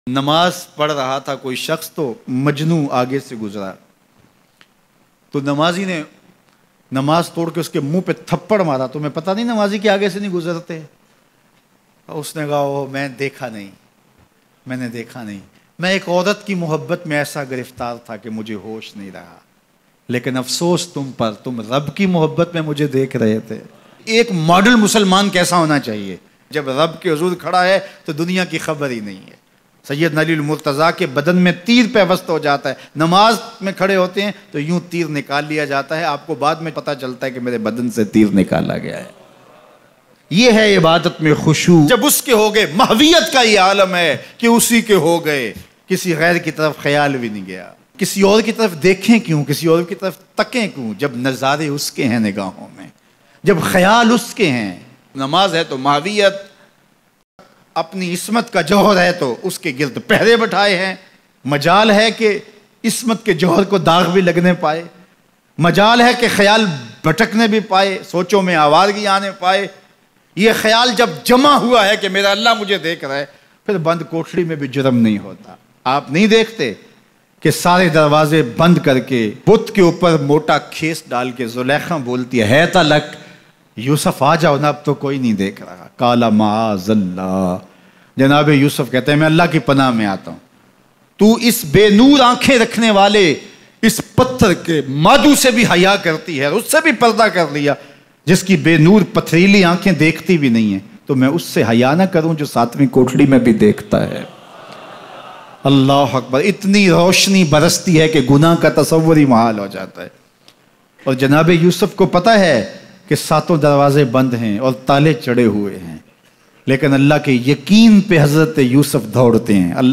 Majnu ka Nmazi se Fikr-Angaiz Swal Bayan